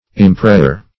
Search Result for " impressure" : The Collaborative International Dictionary of English v.0.48: Impressure \Im*pres"sure\ ([i^]m*pr[e^]sh"[.u]r; 135), n. [Cf. OF. impressure, LL. impressura.] Dent; impression.